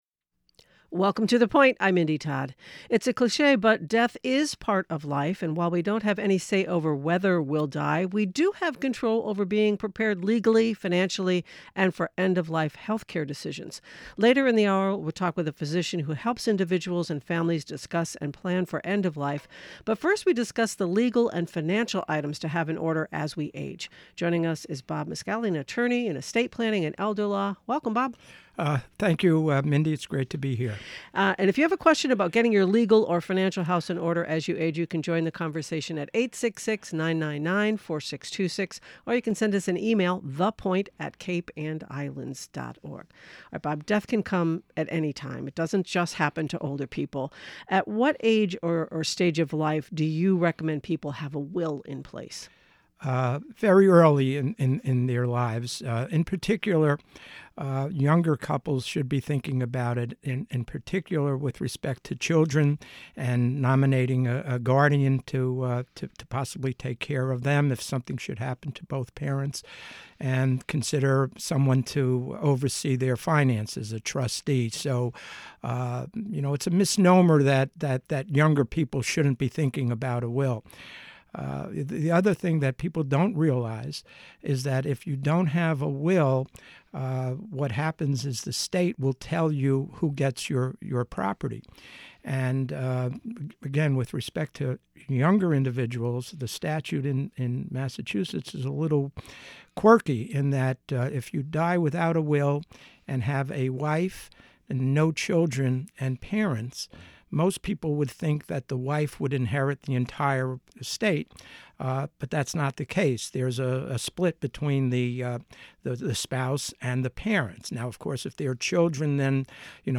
1 The Cape Cod Times - Wednesday, 5-7-25 58:53 Play Pause 15h ago 58:53 Play Pause Play later Play later Lists Like Liked 58:53 This reading is intended solely for People who are Blind and/or Print-Disabled.